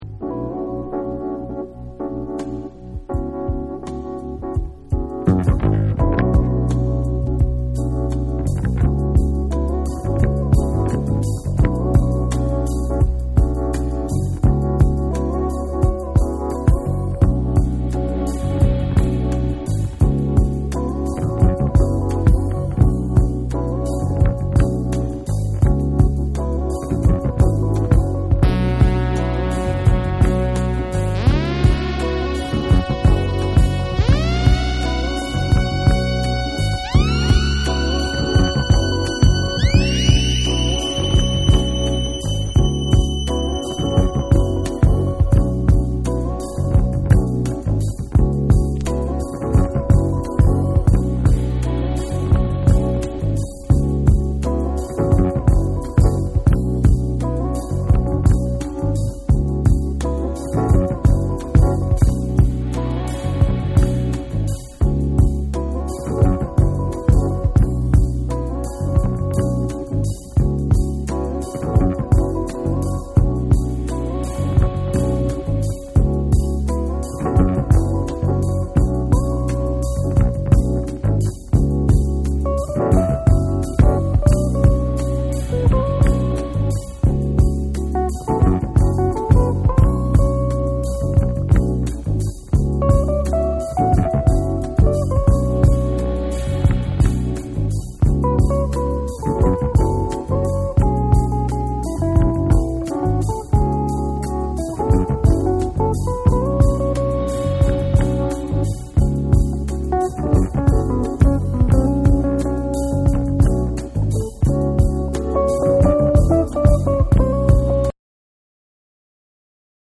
DANCE CLASSICS / DISCO